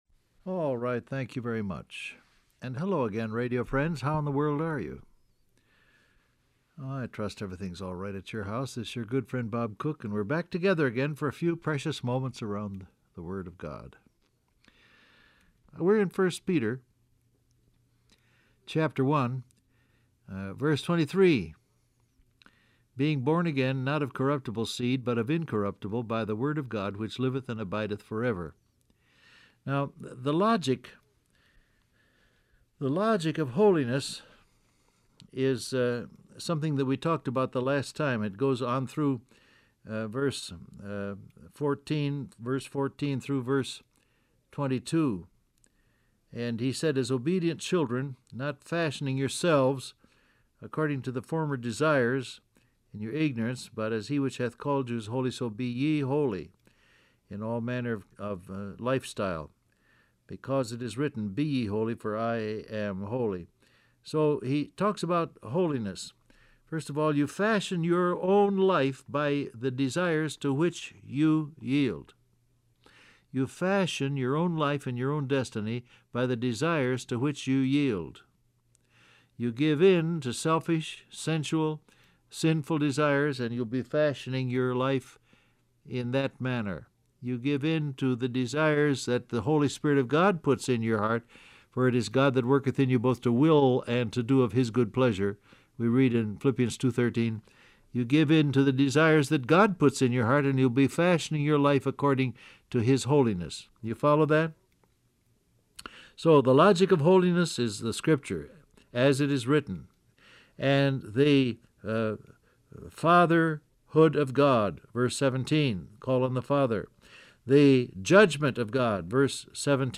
Download Audio Print Broadcast #7133 Scripture: 1 Peter 1:18-23 Topics: Word of God , Born Again , Holinesss , Cost Of Salvation Transcript Facebook Twitter WhatsApp Alright, thank you very much.